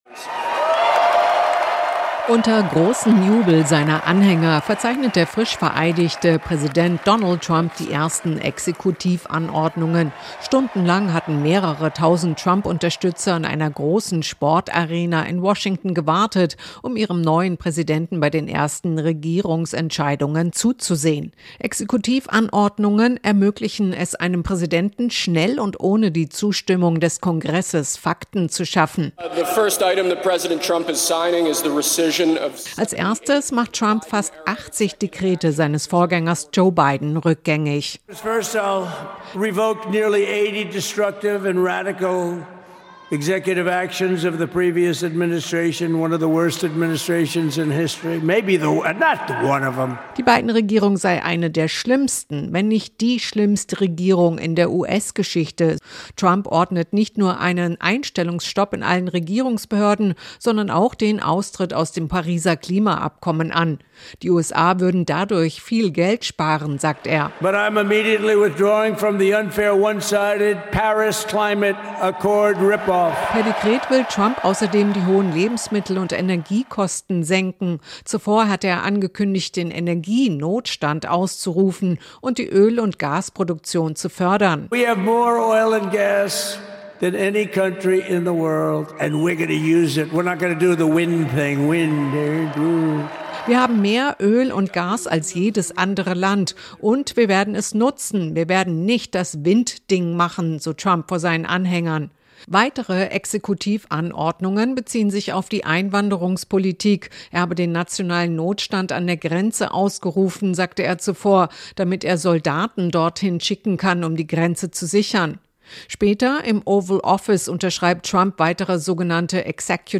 Washington-Korrespondentin